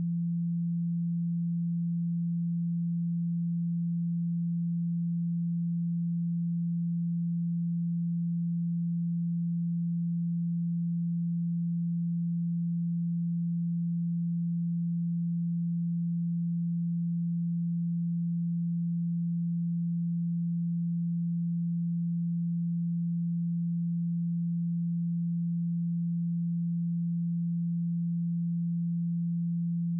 170Hz_-27.dB.wav